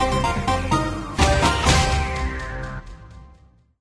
brawl_fighting_load_01.ogg